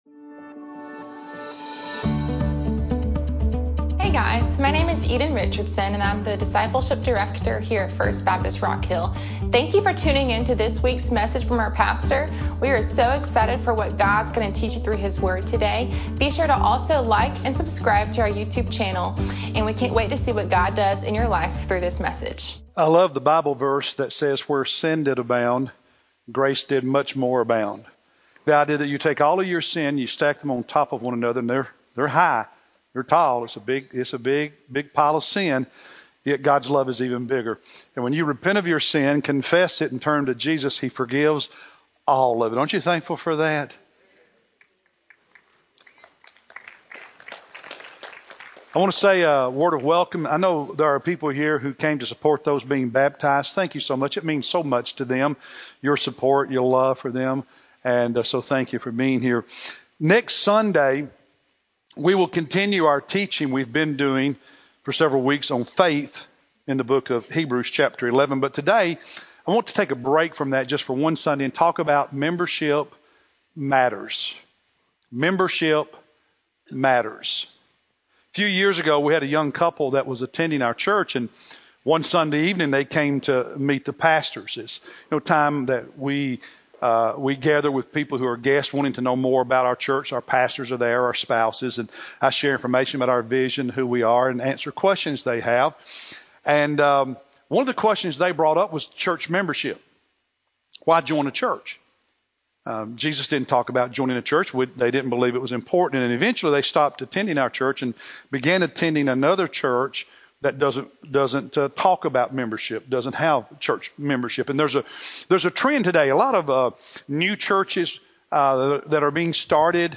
Sept-8-Sermon-Fixed-CD.mp3